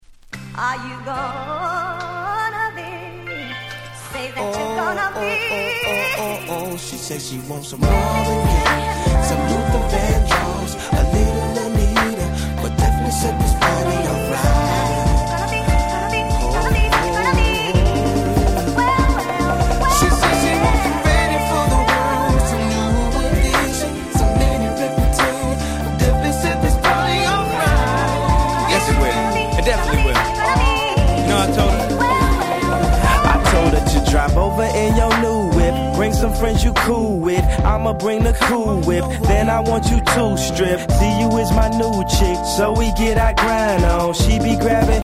03' Super Hit Hip Hop !!